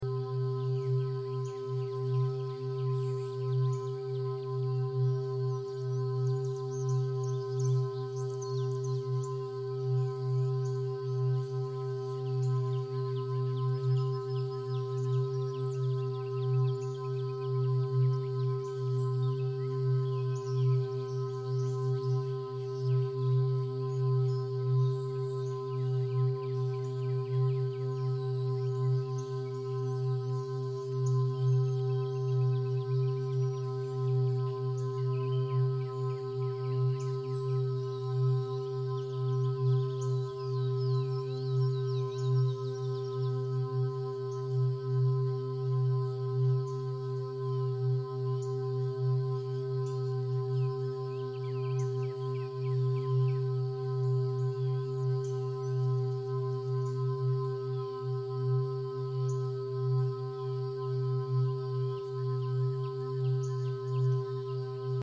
396Hz sacred geometry meditation music